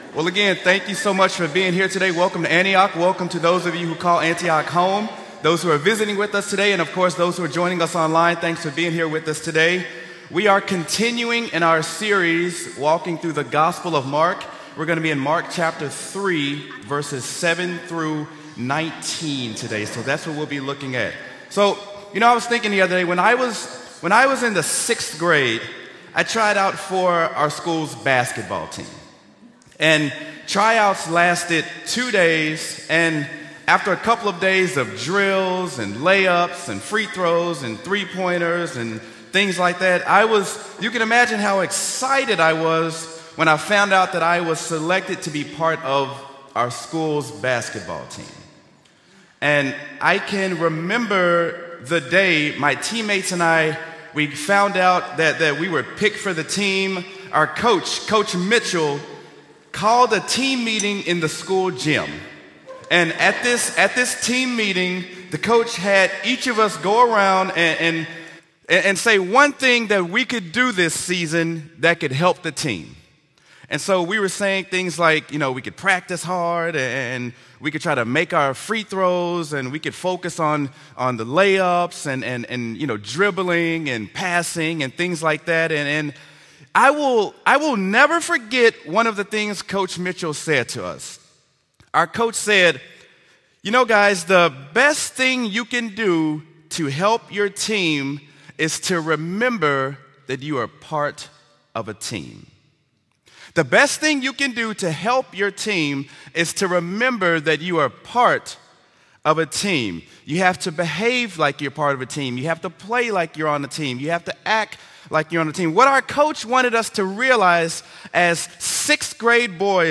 Sermon: Mark: Being a Team Player
sermon-mark-being-a-team-player.m4a